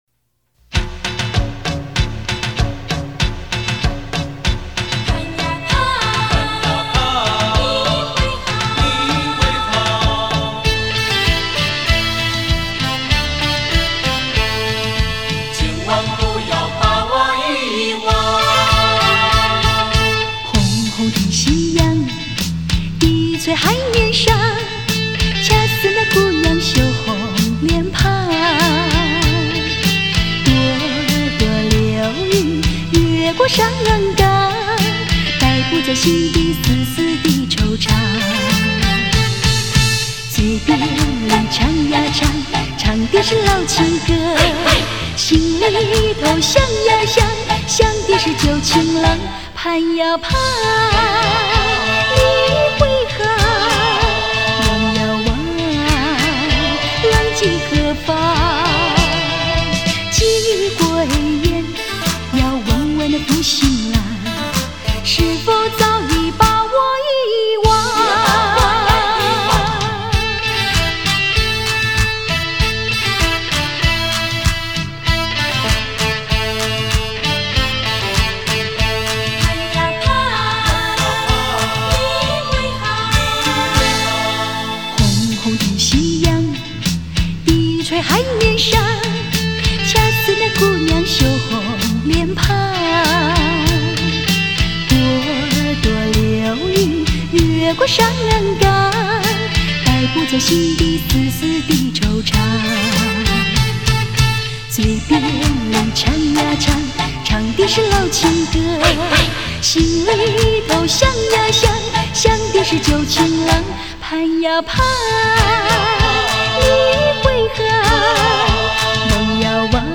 可见她浑厚的歌声深得人心。